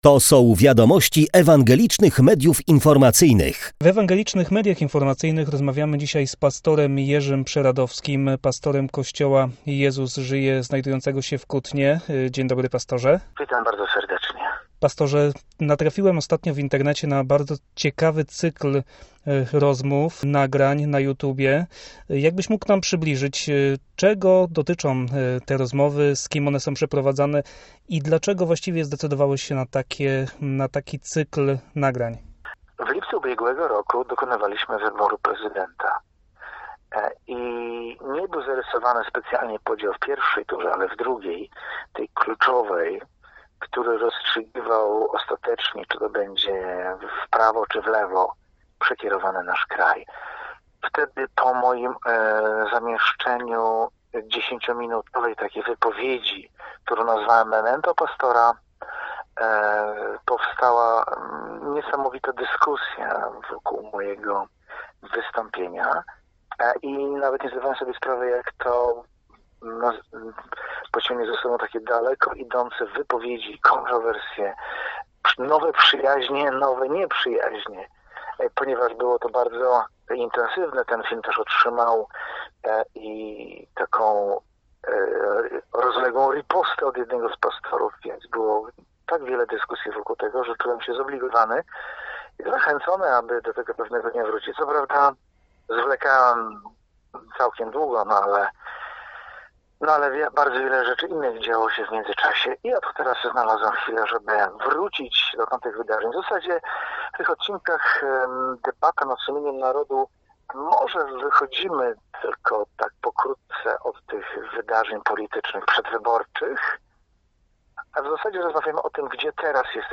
Zapraszamy na rozmowę